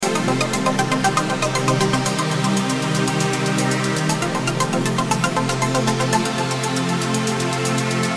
Cybernetic Pulse – 118 BPM High-Energy Electronic Loop
High-energy electronic loop at 118 BPM featuring a driving rhythm and sharp, rhythmic percussion. Layered arpeggiated synths and a deep oscillating bass create a futuristic, intense atmosphere with constant forward motion.
Genres: Synth Loops
Tempo: 118 bpm
Cybernetic-pulse-118-bpm-high-energy-electronic-loop.mp3